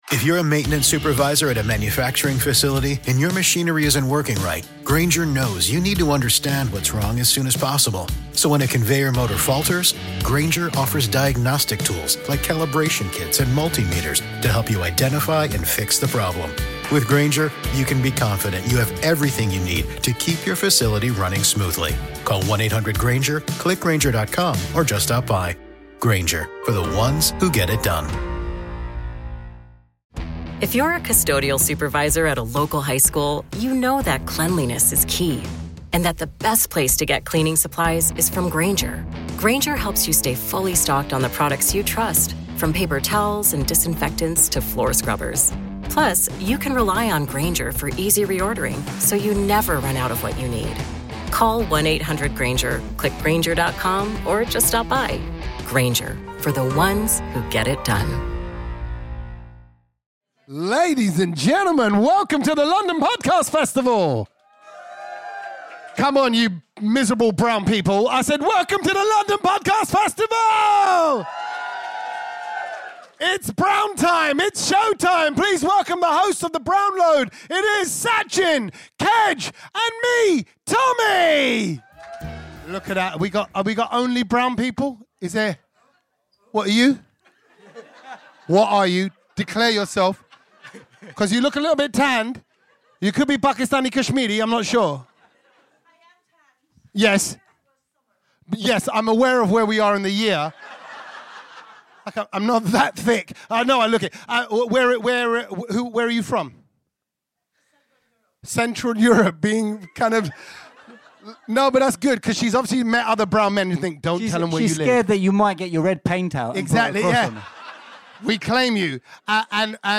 Headliner Embed Embed code See more options Share Facebook X Subscribe Live from the London Podcast Festival in King’s Cross…
You were a wonderful audience and gave us loads of laughs!